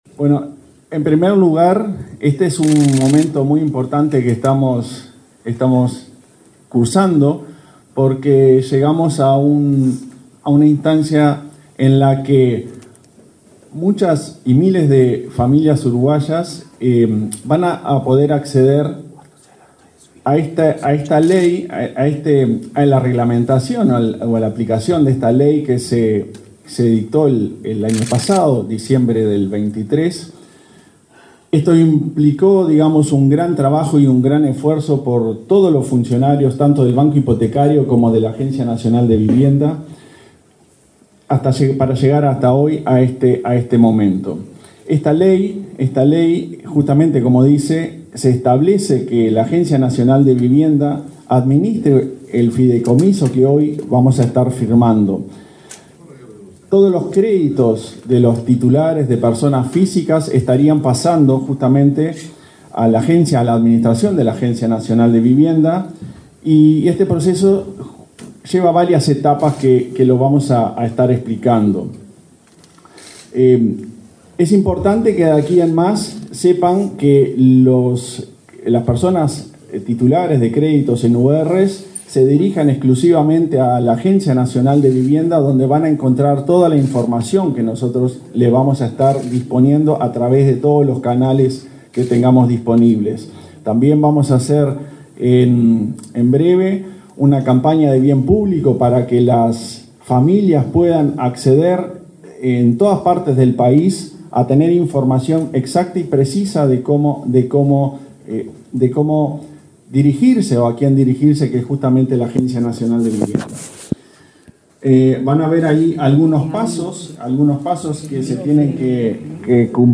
Palabras de autoridades en acto en el Ministerio de Vivienda
El presidente de la Agencia Nacional de Vivienda, Klaus Mill, y el ministro de Vivienda, Raúl Lozano, participaron en la firma de un fideicomiso para